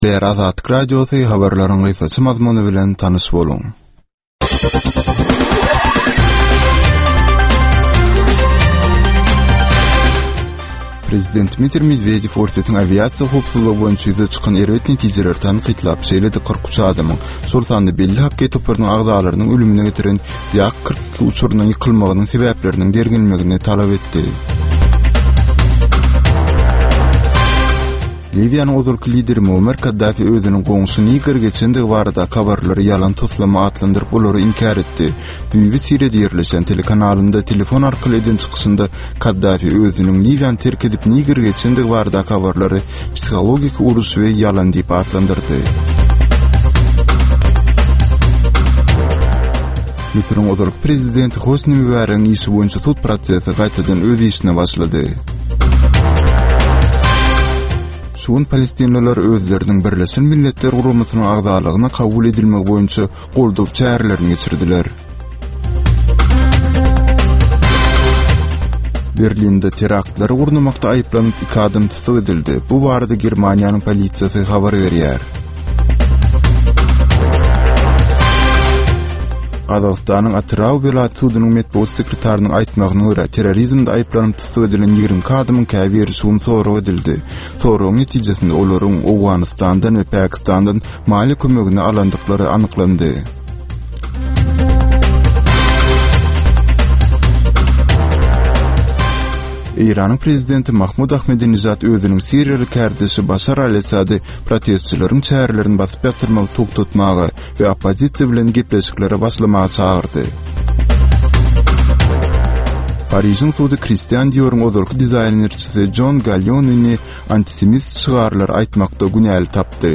Türkmenistandaky we halkara arenasyndaky möhüm wakalar we meseleler barada ýörite informasion-analitiki programma. Bu programmada soňky möhüm wakalar we meseleler barada analizler, synlar, söhbetdeşlikler, kommentariýalar we diskussiýalar berilýär.